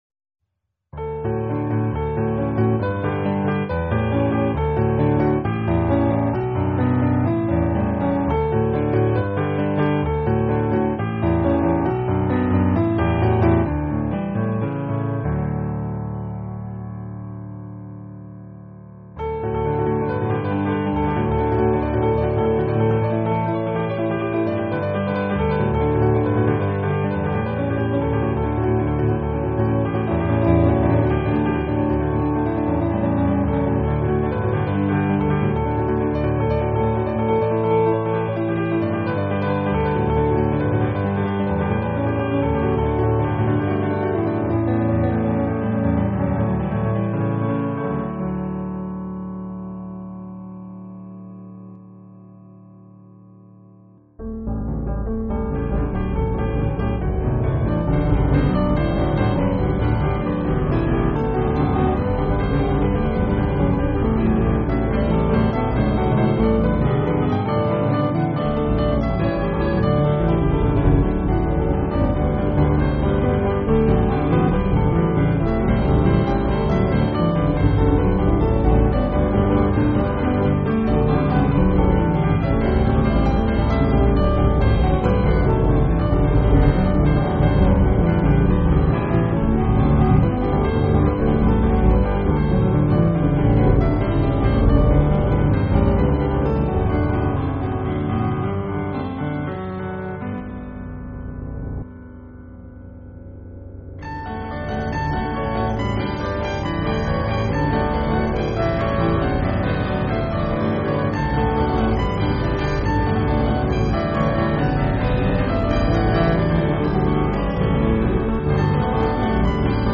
A clock tune in honor of a true man of true science